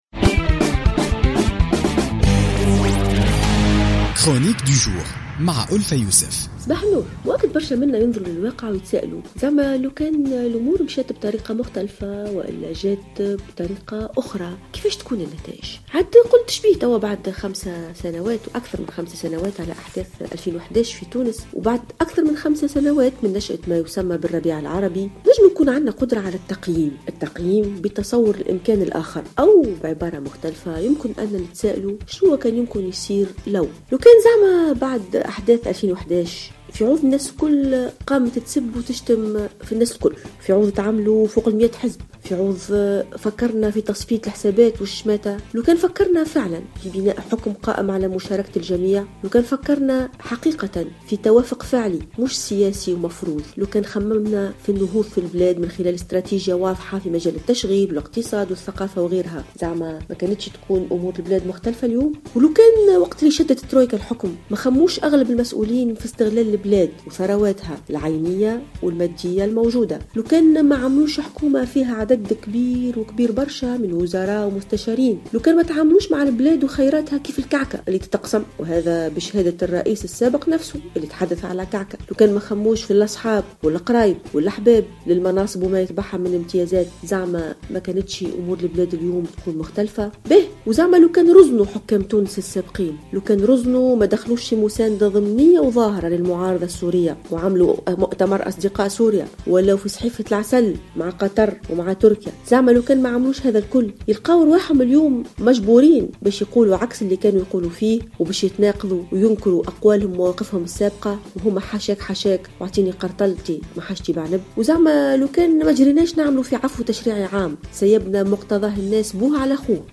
تحدثت الباحثة ألفة يوسف في افتتاحية اليوم الاثنين 4 أفريل 2016 عن الواقع الصعب الذي تعيشه تونس بعد مرور 5 سنوات على الثورة.